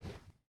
dash.wav